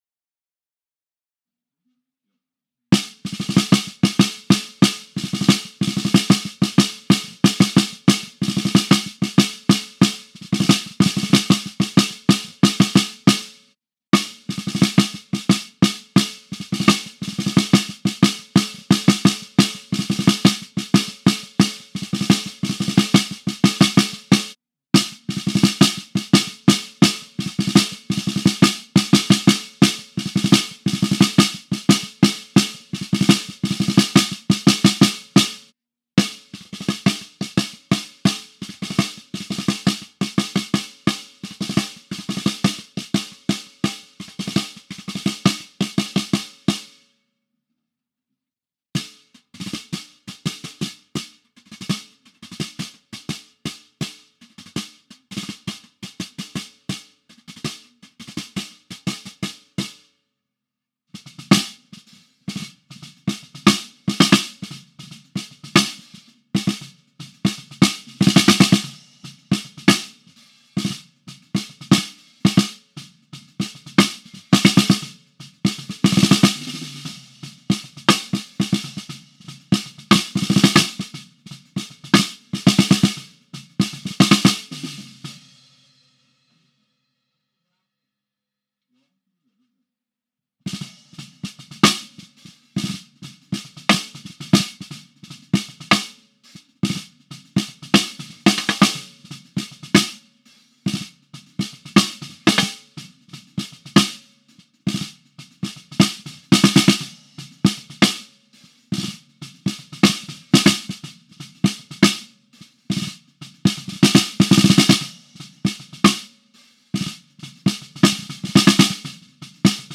Použité subminiaturní a miniaturní mikrofony váží jen pár gramů, takže je gaffou zajištěný nosný drát bez problémů udržel na svém místě zhruba v prostřed bubnu.
Bubínek neboli snare
Rytmus je hraný postupně různými druhy paliček, špejlemi a nakonec štětkami, stopa je zakončena ráfkem. Všimněte si, že to prakticky nepoznáte, rozdíl ve zvuku je minimální.